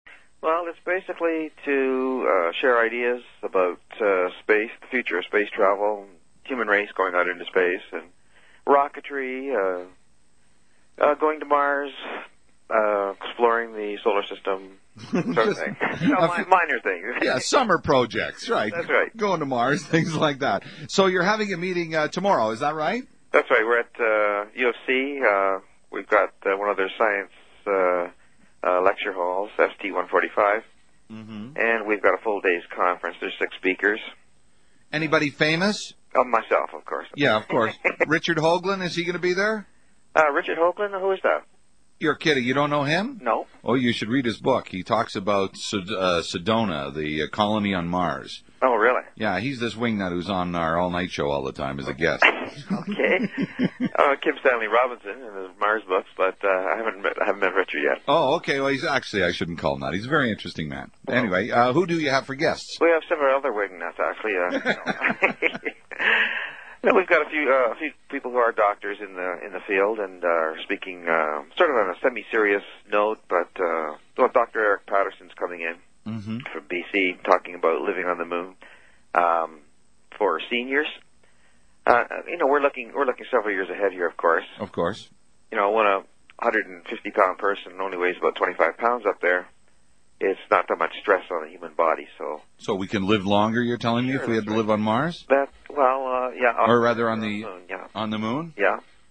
Canadian English, general
The main phonetic feature which distinguishes Canadians from Americans is the centralised onset of the diphthongs /ai/ and /au/ when followed by a voiceless segment, e.g. like [ləɪk] and about [əˡbəʊt]. This centralisation is not found before voiced segments, e.g. tide [taɪd] and loud [laʊd].
Canada_Calgary_General.wav